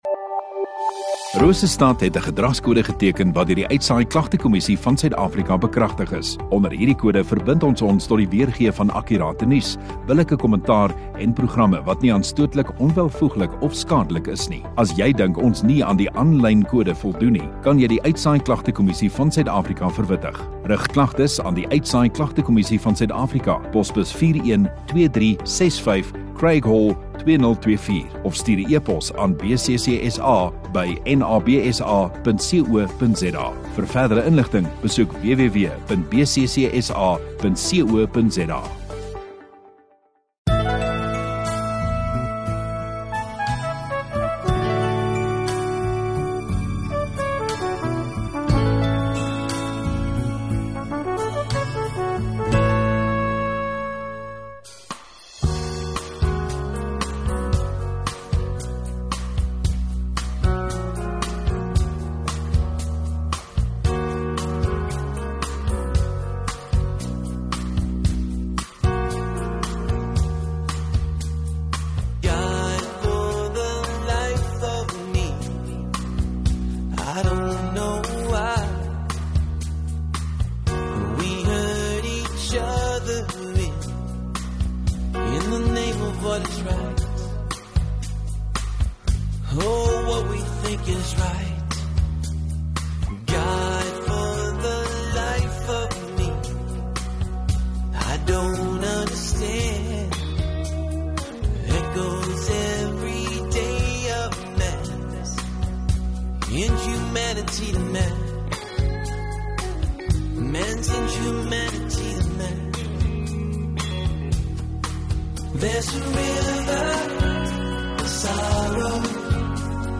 29 Oct Sondagoggend Erediens